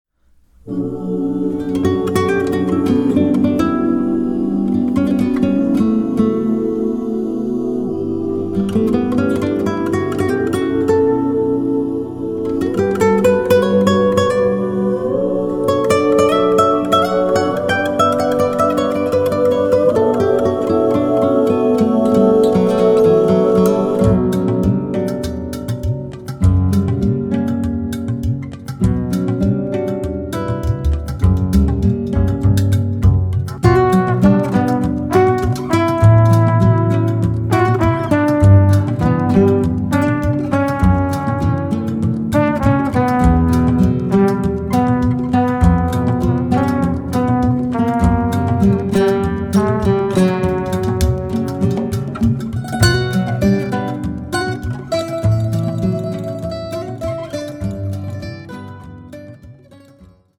flamenco-gitarre, laúd, perc.
kontrabass, e-bass
percussion